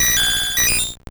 Cri de Porygon dans Pokémon Or et Argent.